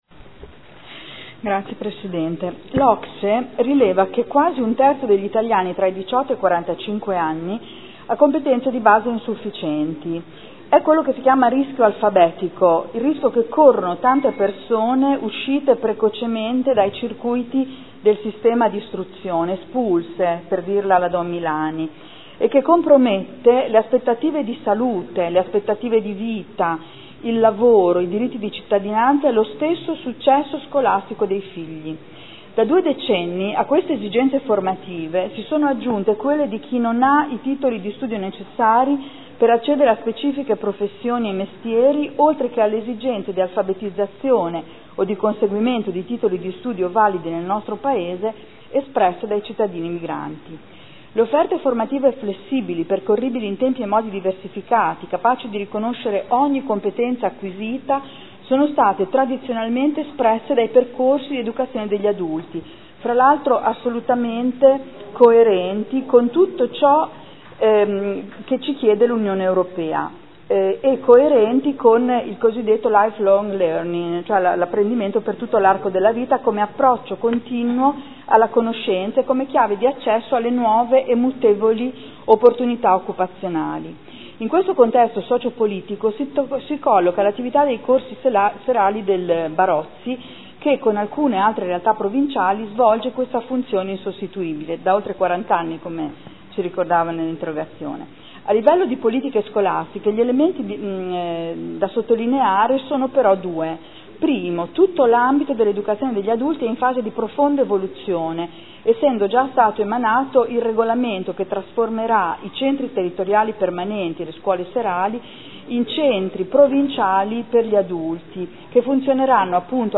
Adriana Querzè — Sito Audio Consiglio Comunale